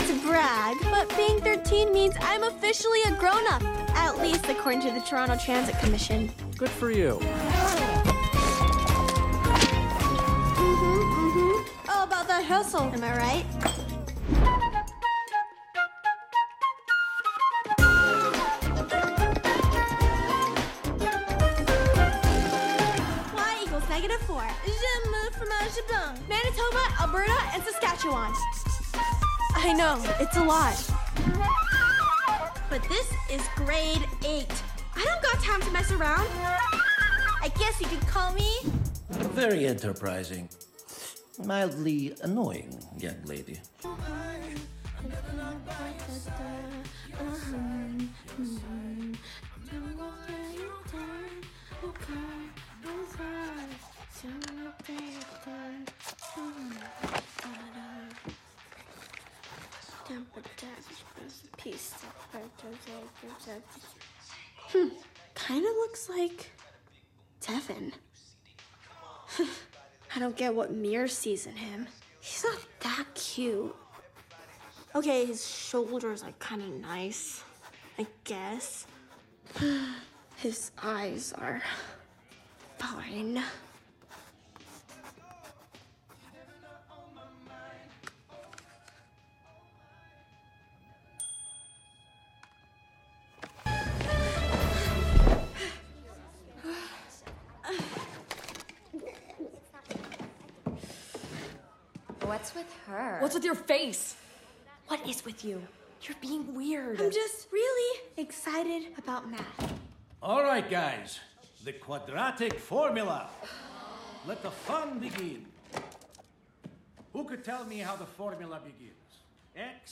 There are the signs like ``I ate some pie" or the 1980 Winter olympics poster where the US won against the Soviets, the pun being that the math teacher has a Russian accent.